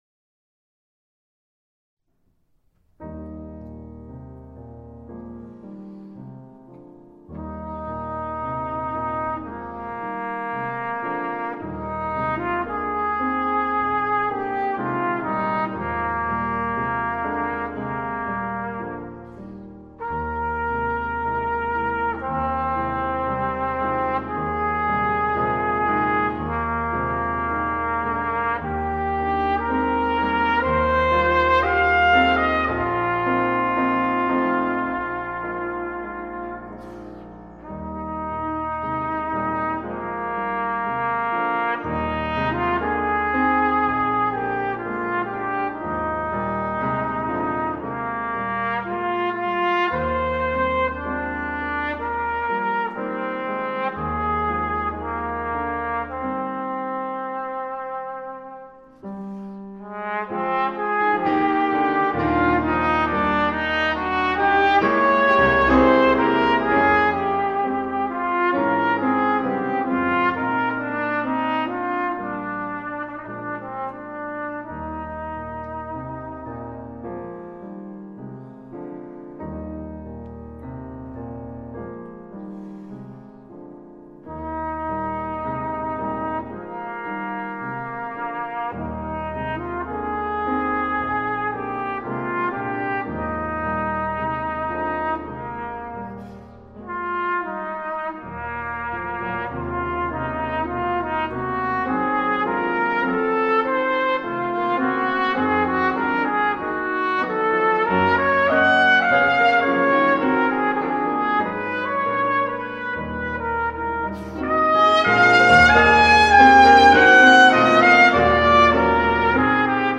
ESTRUCTURA:  Rondó. A-B-A-C-A
PIANO: Família de la corda percudida
trompeta TROMPETA: Família del vent metall
Berceuse-Trompeta-i-piano.mp3